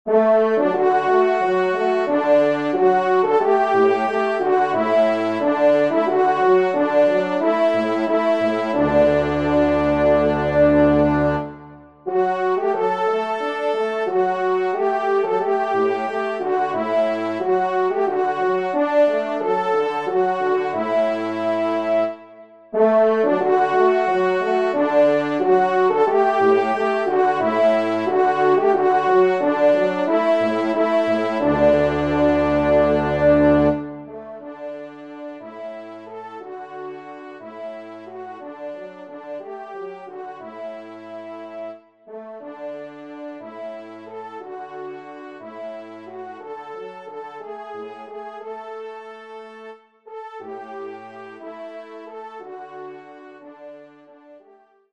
1e Trompe